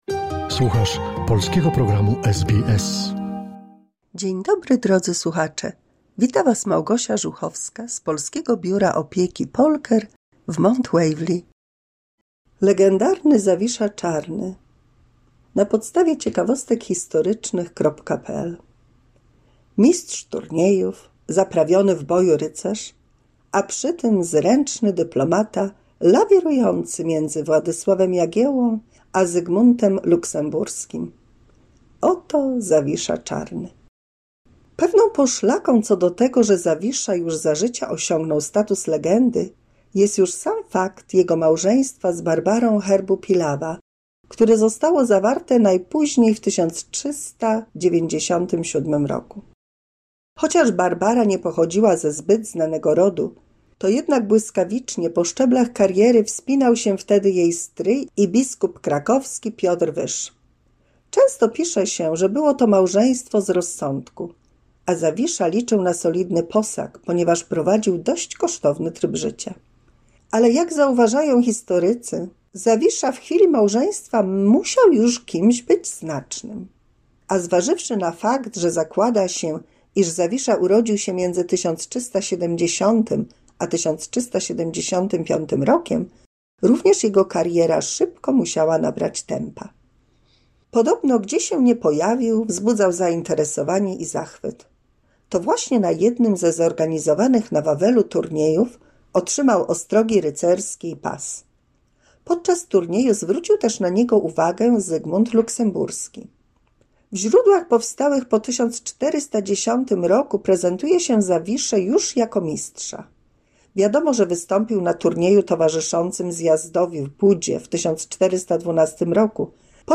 W 208 mini słuchowisku dla polskich seniorów usłyszymy historię o Zawiszy Czarnym, dzielnym rycerzu...